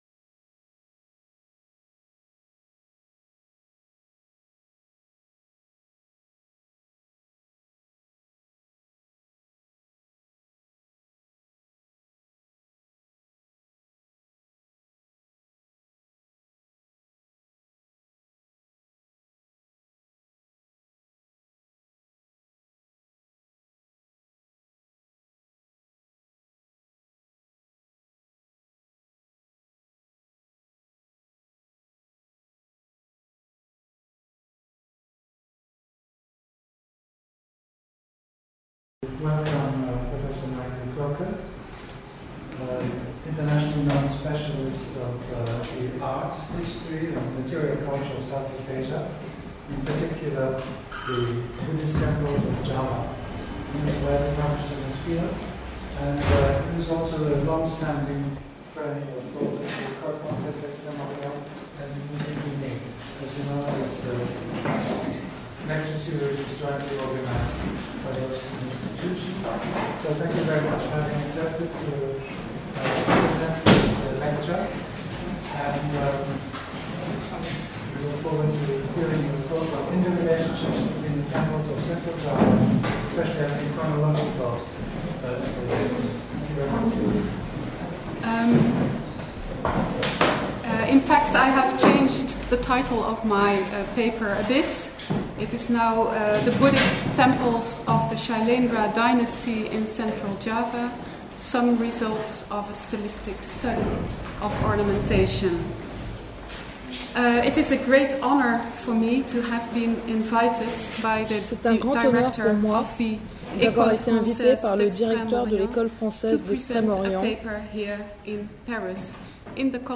In this lecture I wish to contribute to the discussions on interrelationships between Central Javanese temples on the basis of a study of the ornamentation adorning the Central Javanese temples.